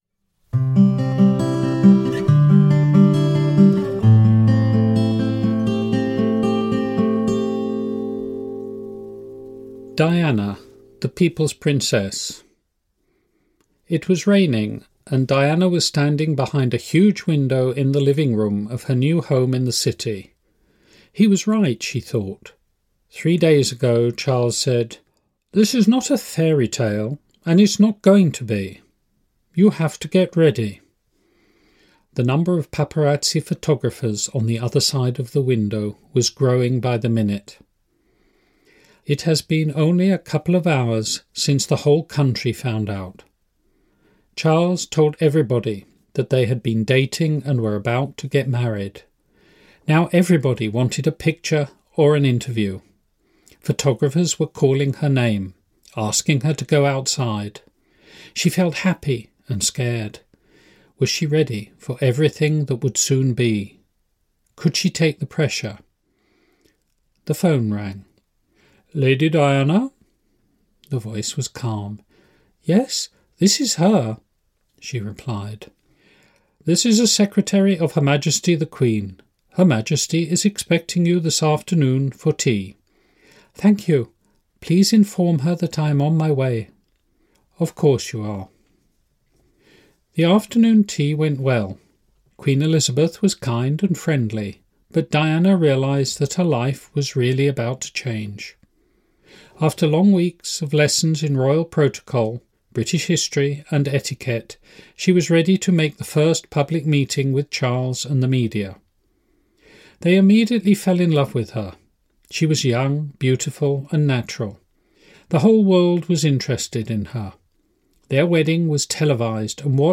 Audiokniha je načtená rodilým mluvčím.
Audio kniha
Ukázka z knihy